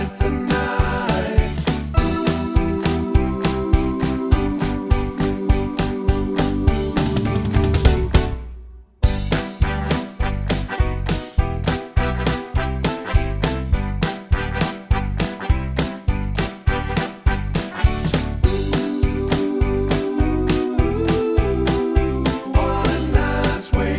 Two Semitones Down Pop (1980s) 3:37 Buy £1.50